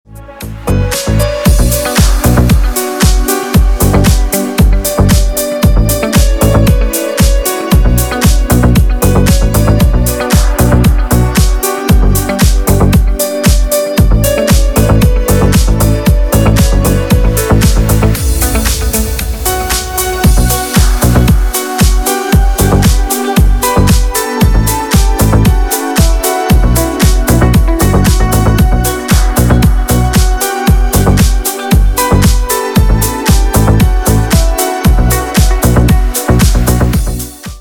Рингтоны 80-х